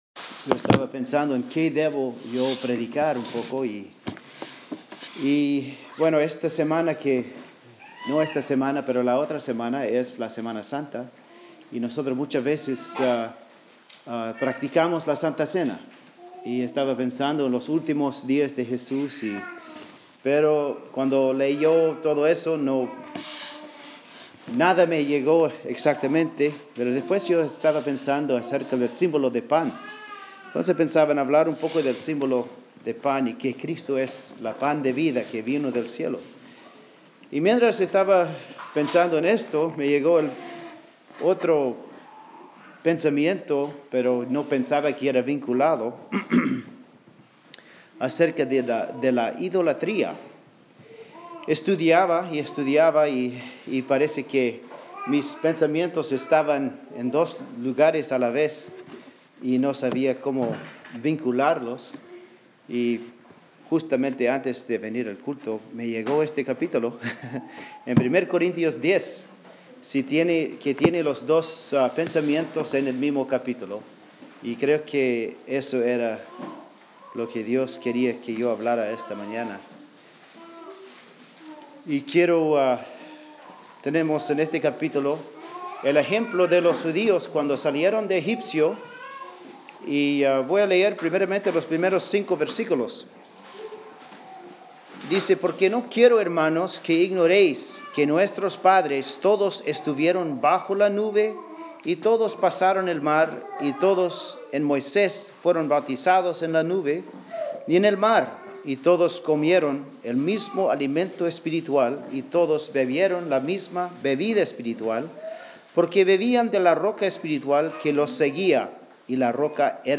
Sermones de un Pastor Anabaptista (Anabautista) Menonita en Espanol